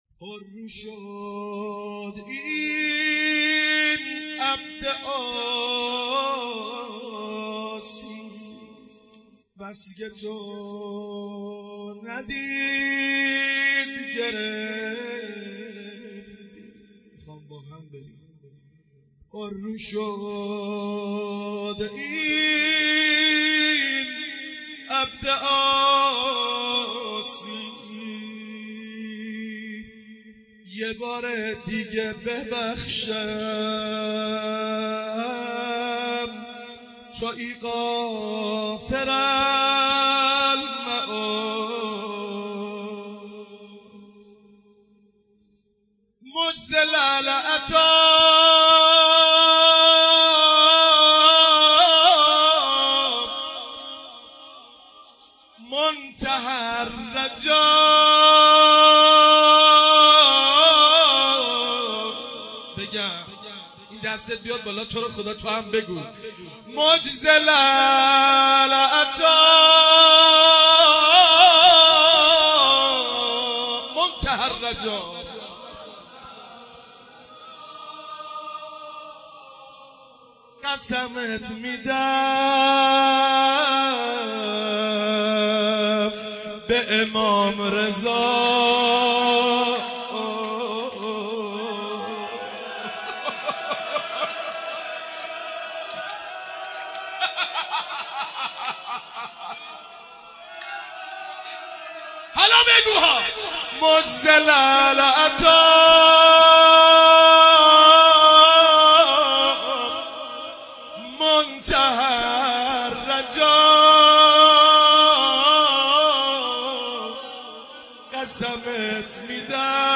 مناجات خوانی